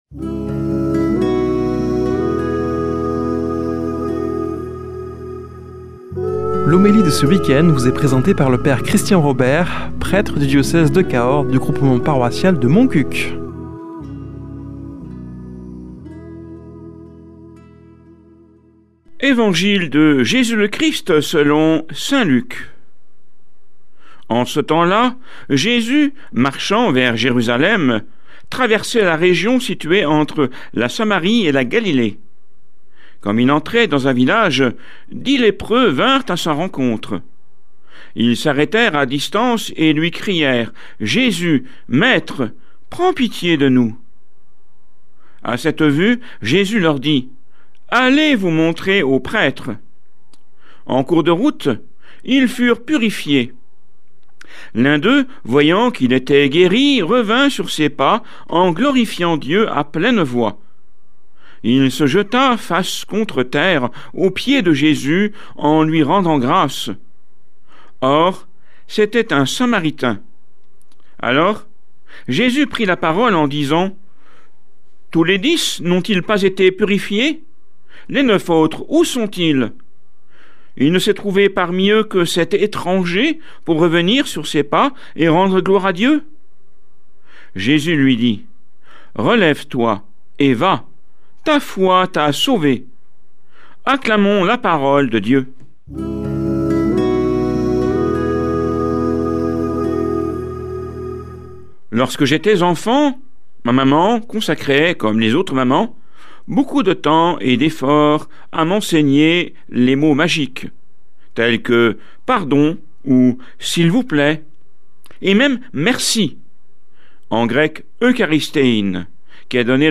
Homélie du 11 oct.